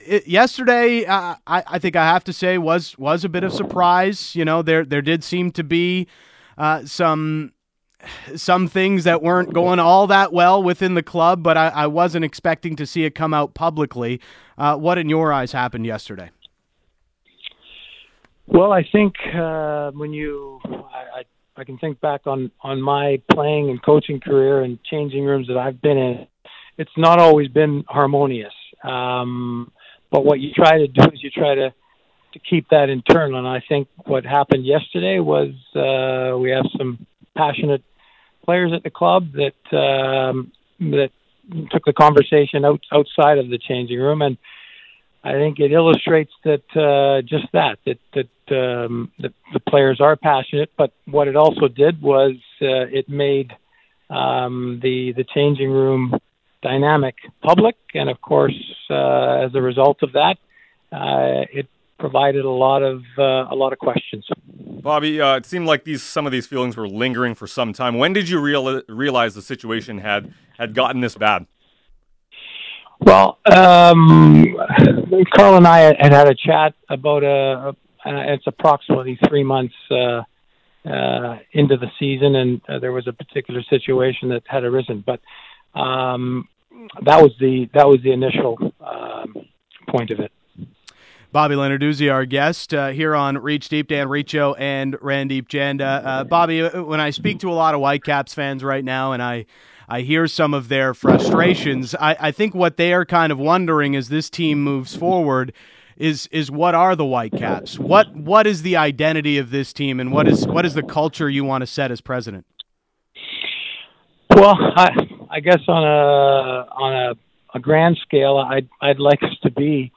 Vancouver Whitecaps president Bob Lenarduzzi joined Sportsnet 650 to give his take on the players publicly expressing their displeasure with the 2018 season. Lenarduzzi also explained how the club will move forward, and where money will be spent.